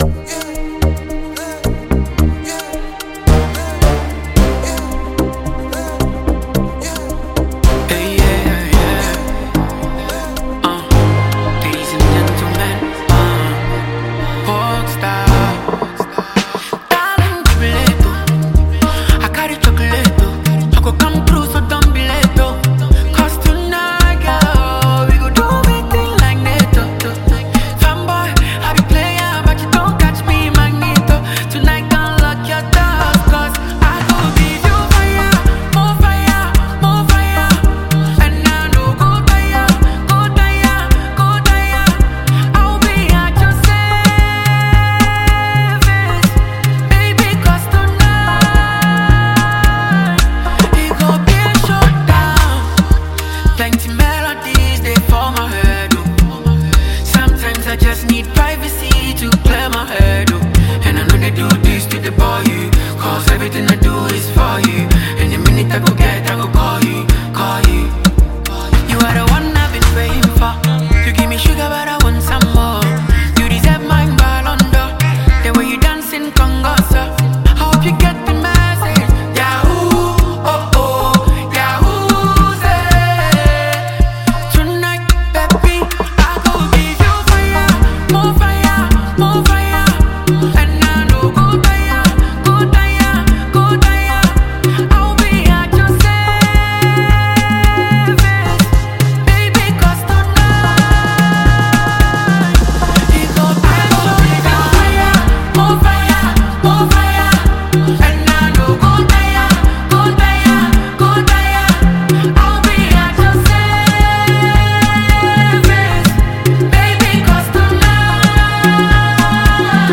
and it will have you dancing and moving in no time at all.